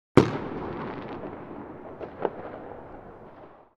Single Loud Distant Firecracker Explosion In City Sound Effect
Description: Single loud distant firecracker explosion in city sound effect. Realistic single loud firecracker explosion with long echo and New Year’s ambience in the background, recorded at a distance in a city environment.
Single-loud-distant-firecracker-explosion-in-city-sound-effect.mp3